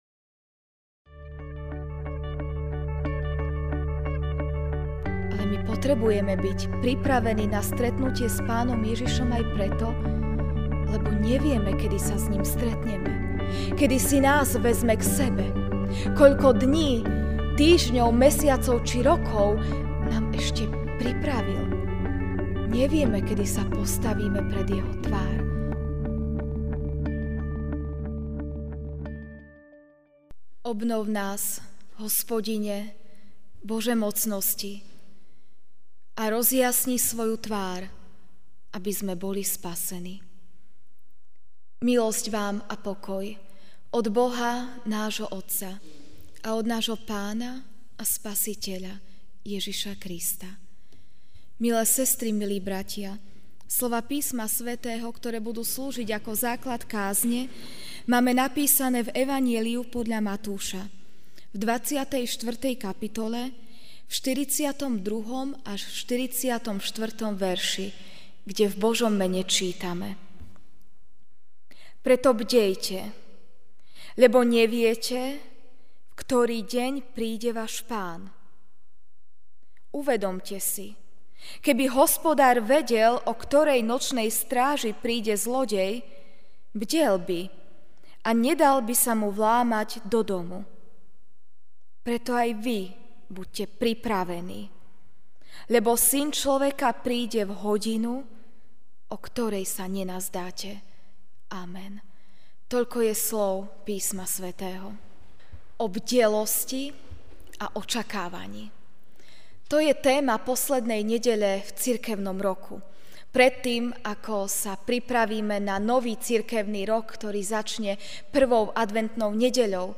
Večerná kázeň: Byť pripravený/á (Mt 24, 42-44) Preto bdejte, lebo neviete, v ktorý deň príde váš Pán.